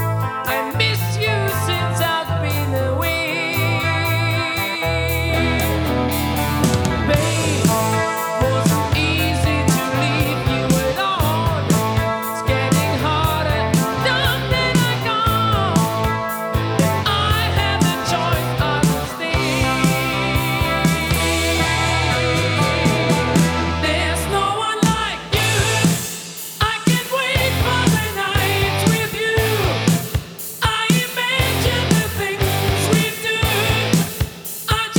Жанр: Рок / Альтернатива / Метал